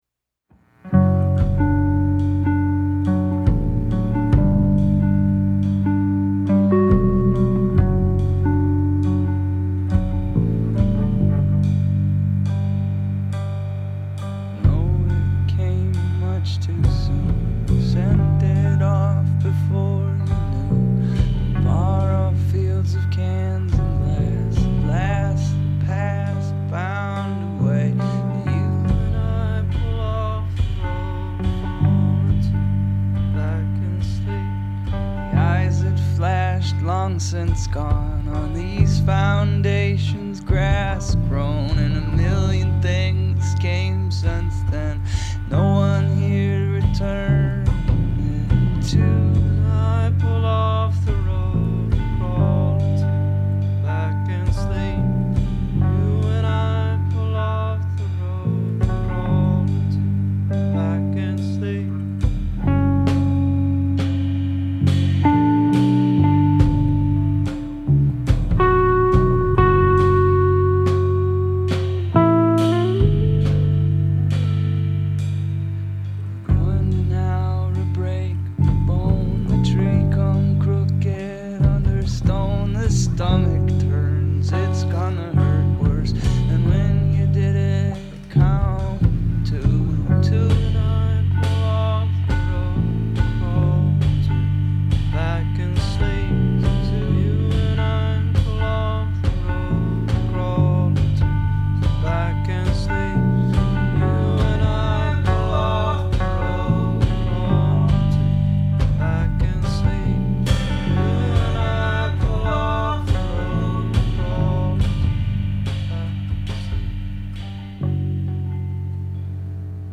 The songs were recorded live